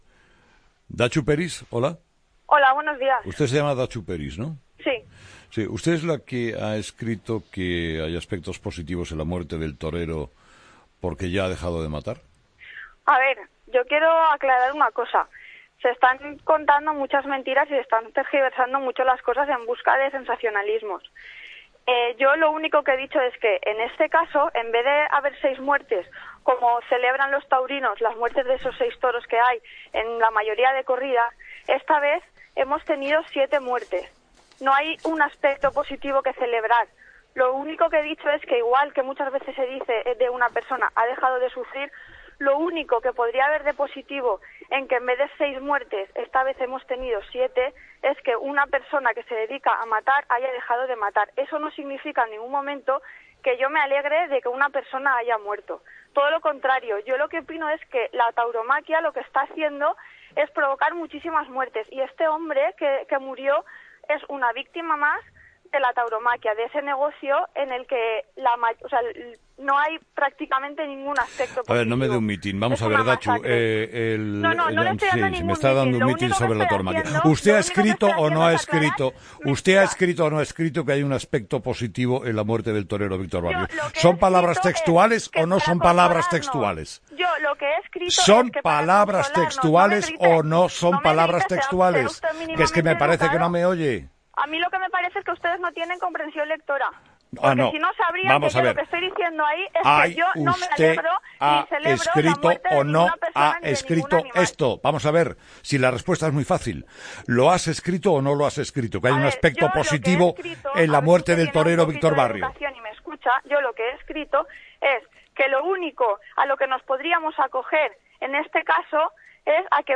Entrevista a Datxu Peris
Entrevista a la concejal Datxu Peris tras sus comentarios en Facebook sobre los 'aspectos positivos' de la muerte de Víctor Barrio